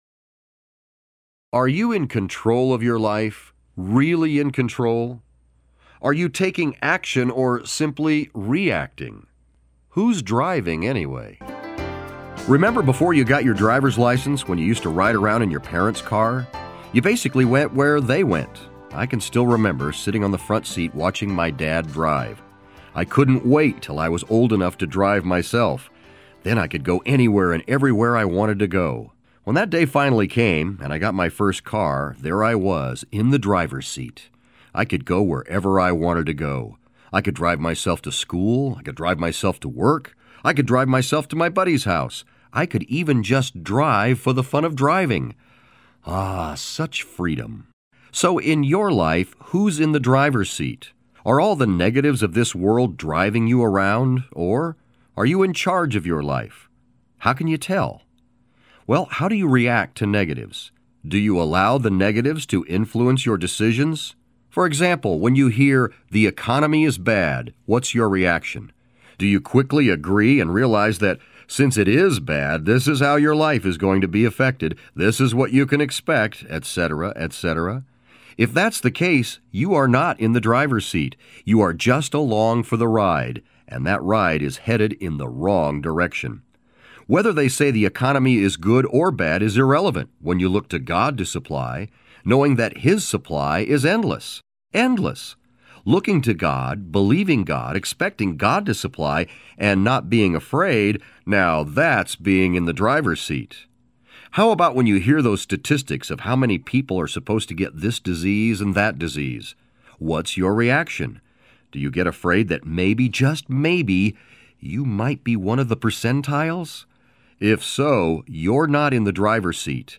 Those pep talks are 3 to 5 minutes of inspiring and practical information.  Here’s a sample from, Pep Talks for Christians, Volume II, called “Who’s Driving?”